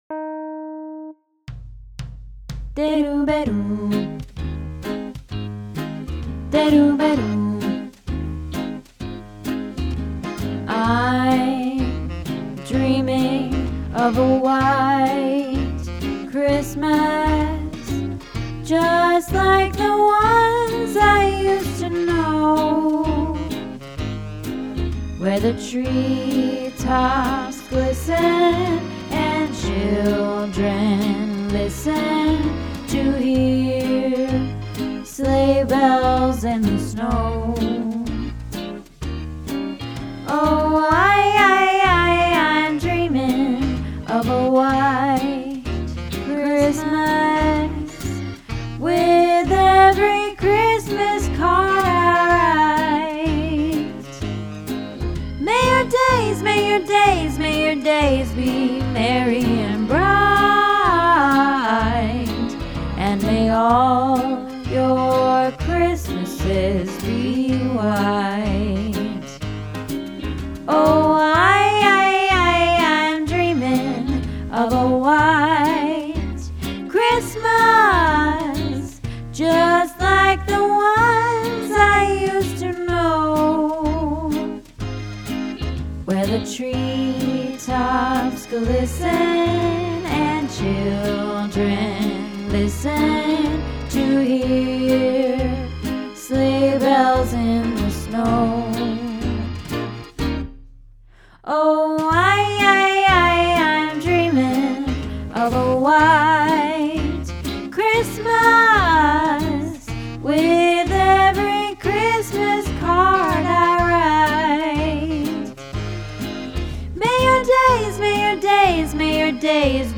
White Christmas - Alto